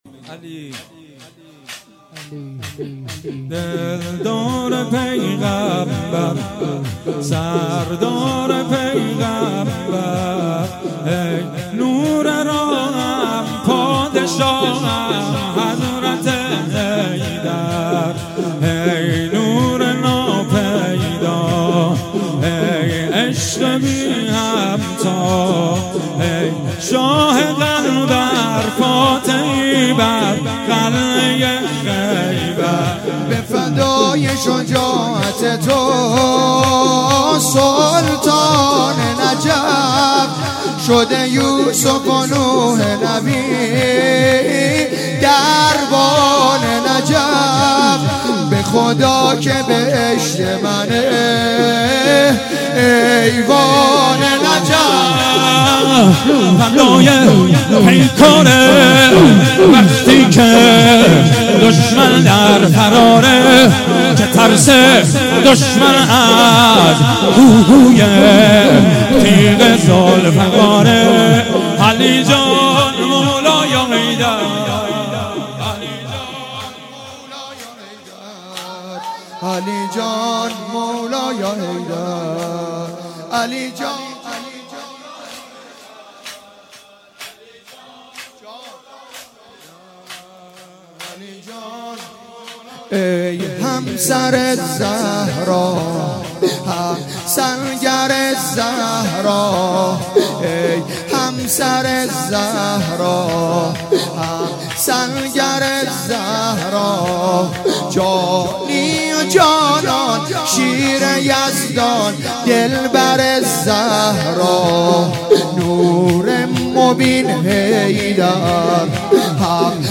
روضه انصارالزهرا سلام الله علیها
همخوانی